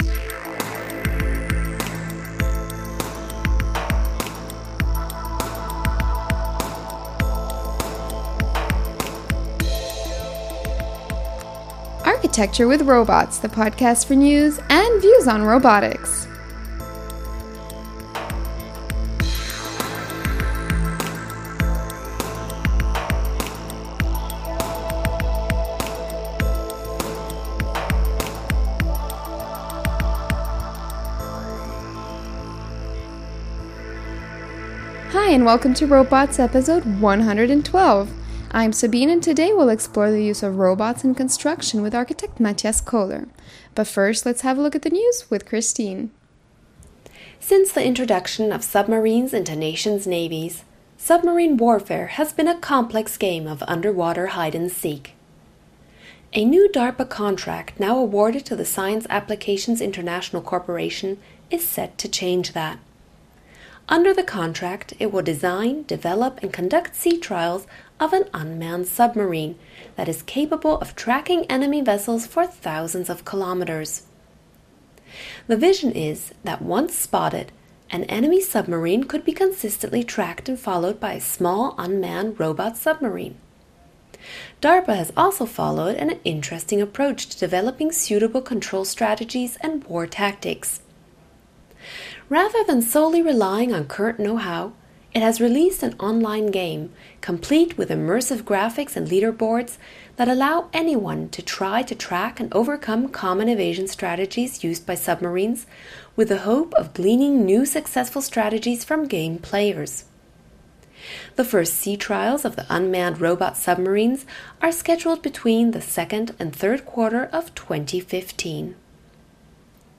The issues of safety, compliance with building codes and quality control are other interesting topics that we get to hear more about in this interview.